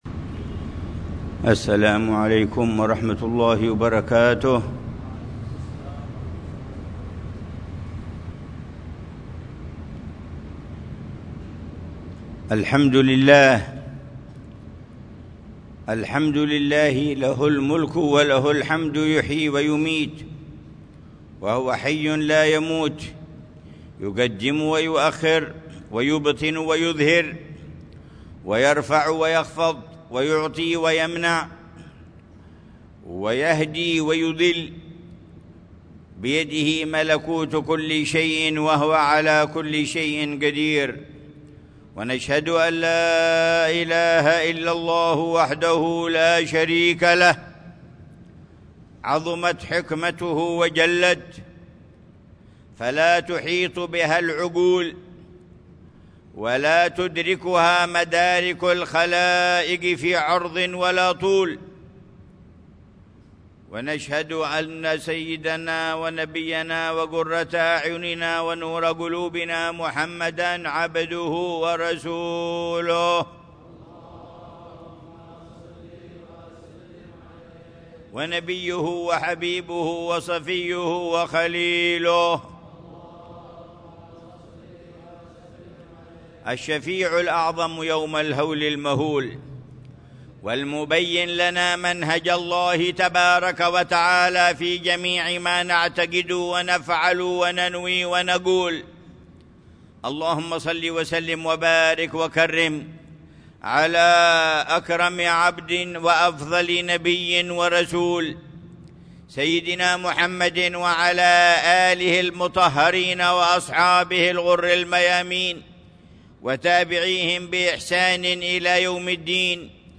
خطبة الجمعة للعلامة الحبيب عمر بن محمد بن حفيظ في جامع الإيمان في عيديد، مدينة تريم، 14 صفر الخير 1447هـ ، بعنوان: